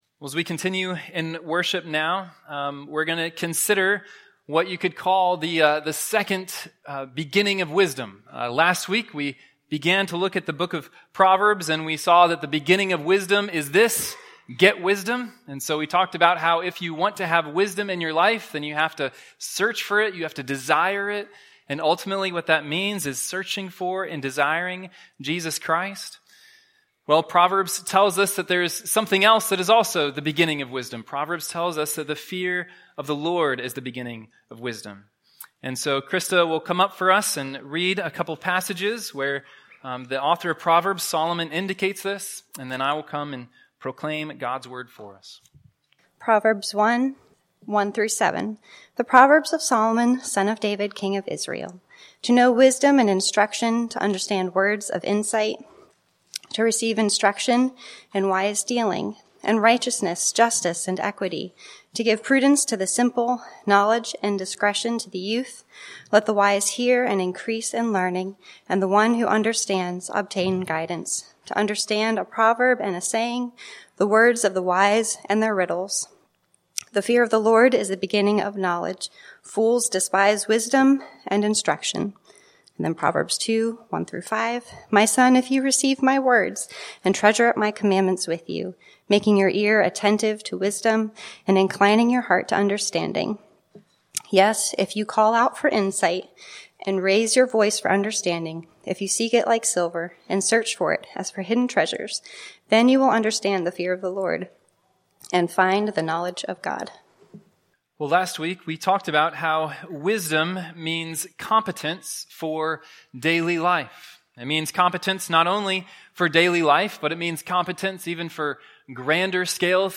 Latest Sermon “Fear the Lord!” Proverbs 1:1—7